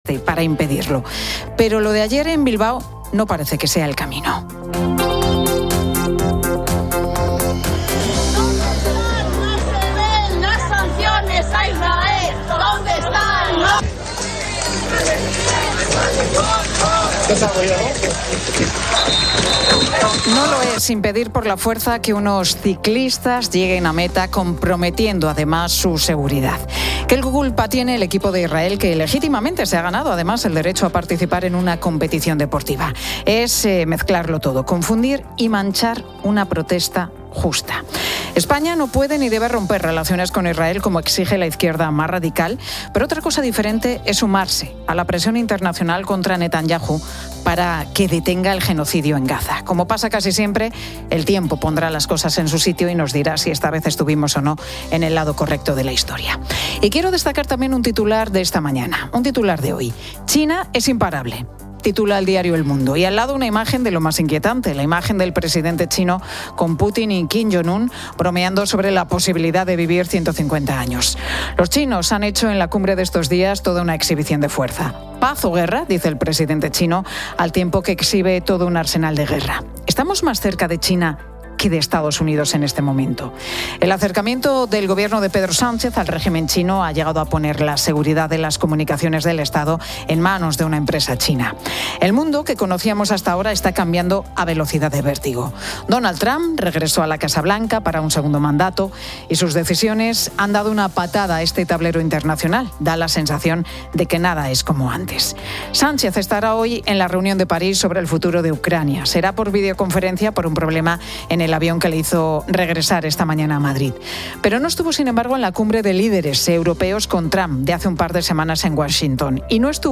La tertulia aborda la situación política internacional de España. Se discute la postura de España ante el conflicto en Gaza y las relaciones con Israel, así como el acercamiento del gobierno de Pedro Sánchez a China y sus implicaciones.